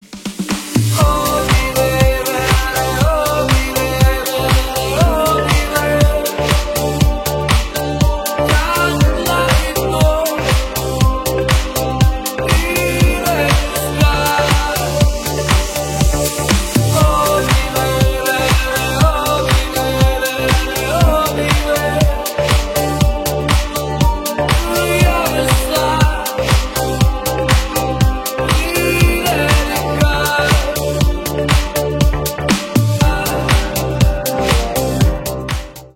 • Качество: 320, Stereo
ритмичные
мужской вокал
deep house
восточные мотивы
Electronic
EDM
монотонные
Стиль: deep house